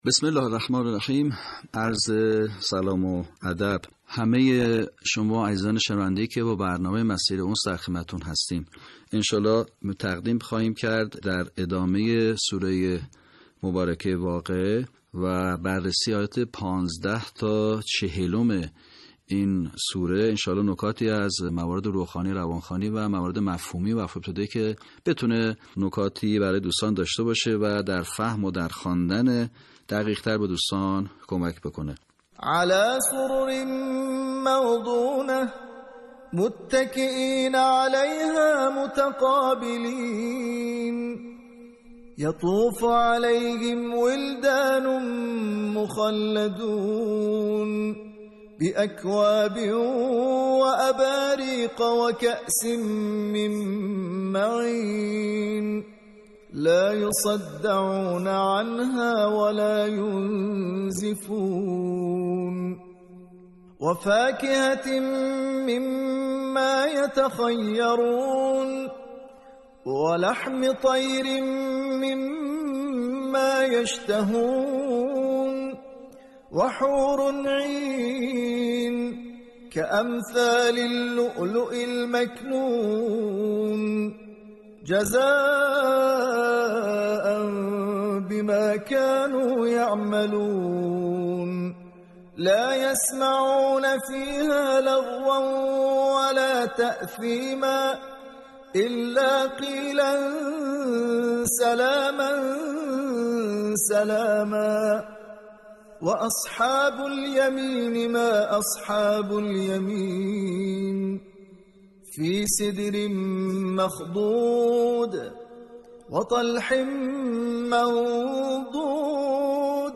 صوت | آموزش صحیح‌خوانی آیات ۱۵ تا ۴۰ سوره واقعه
به همین منظور مجموعه آموزشی شنیداری (صوتی) قرآنی را گردآوری و برای علاقه‌مندان بازنشر می‌کند.